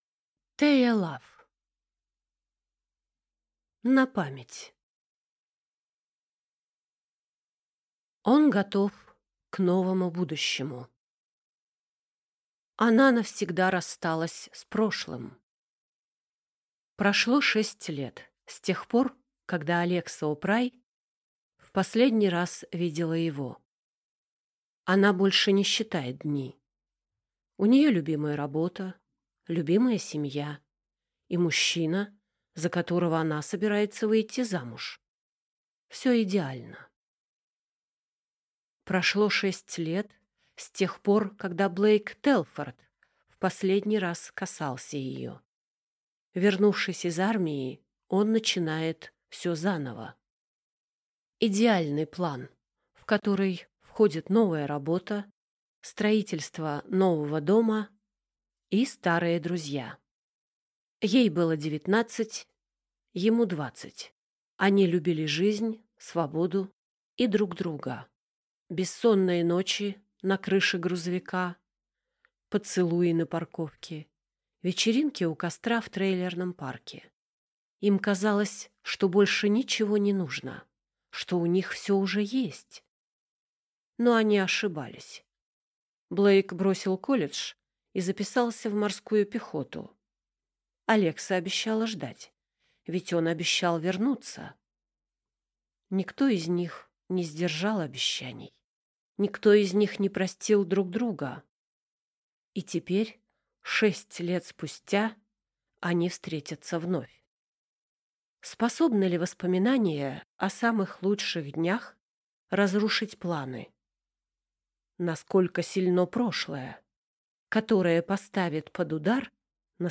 Аудиокнига На память | Библиотека аудиокниг